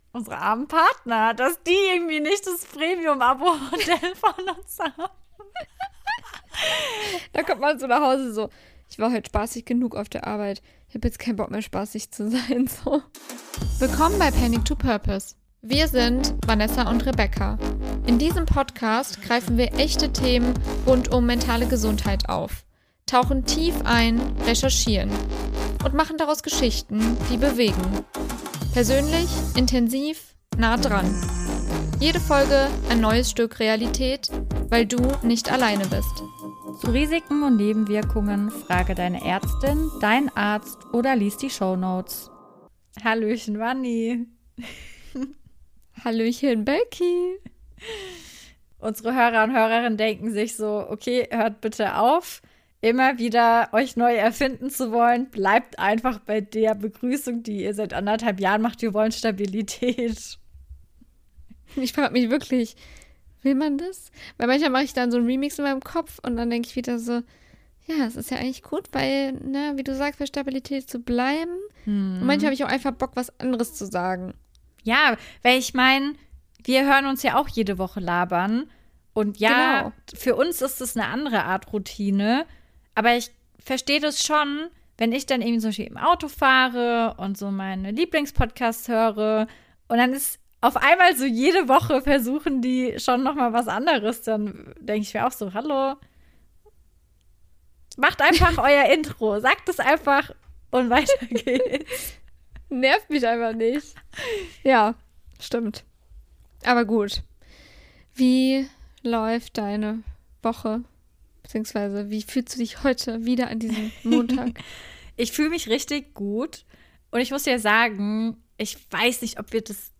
In dieser Laberfolge sprechen wir ehrlich, ungefiltert und teilweise auch unerwartet tief über Themen, die uns alle betreffen.
Eine Mischung aus Deep Talk, Realtalk und klassischer Laberfolge – ehrlich, nahbar und mit Gedanken, die hängen bleiben.